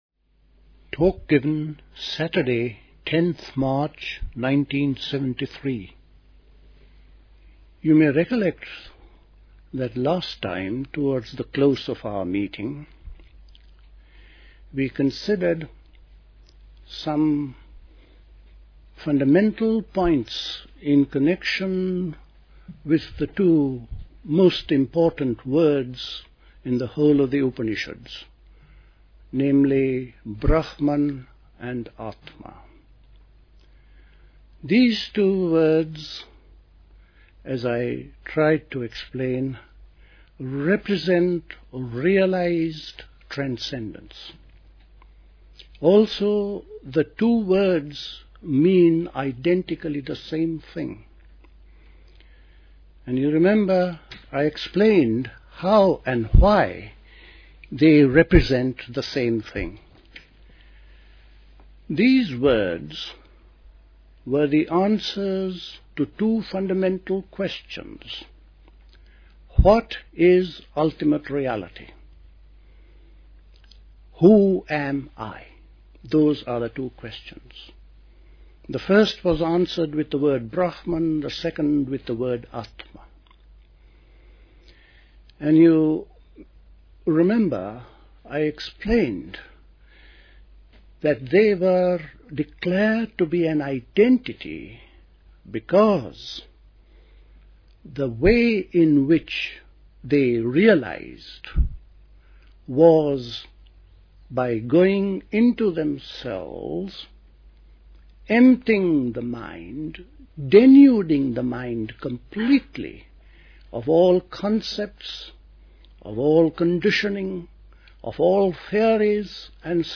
A talk
at Dilkusha, Forest Hill, London on 10th March 1973